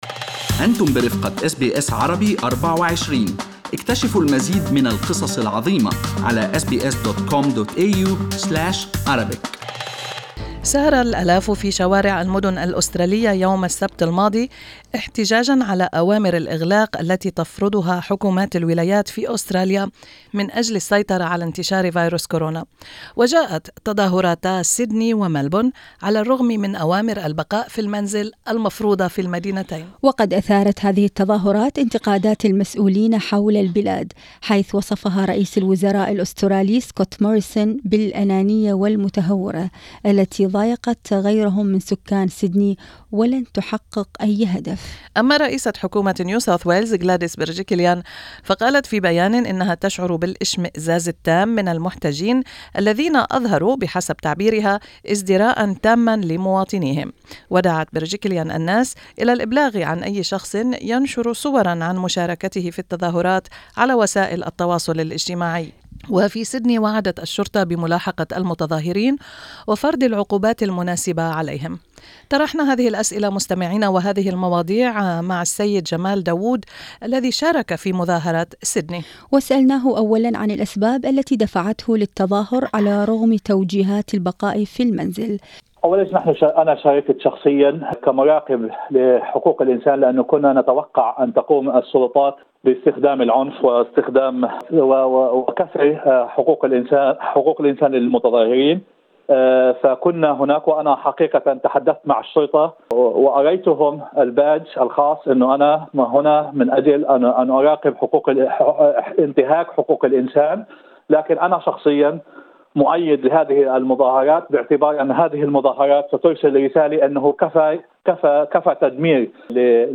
أحد المشاركين في المظاهرة التي سارت في مدينة سيدني وطالبت برفع قيود كورونا يتحدث عن أسباب التظاهر رغم أوامر البقاء في المنزل ويشرح مطالب المتظاهرين.